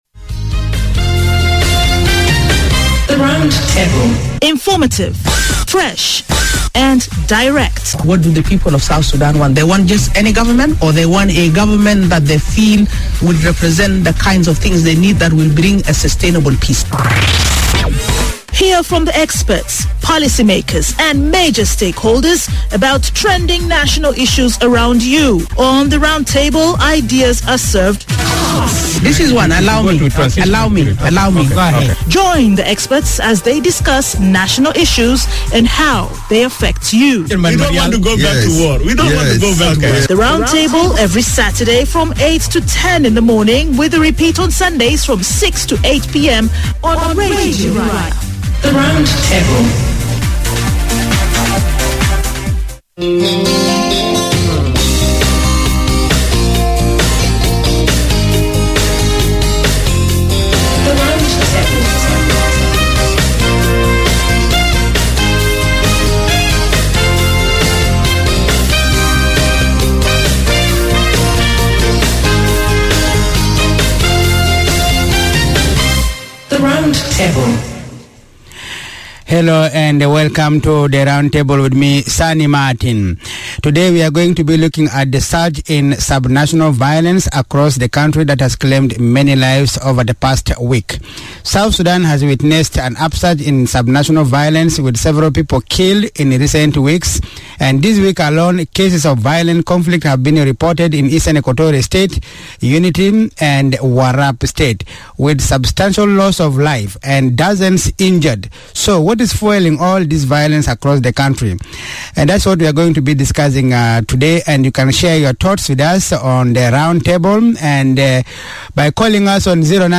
Round Table - Sub-national Violence Discussion Part One